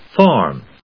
/fάɚm(米国英語), fάːm(英国英語)/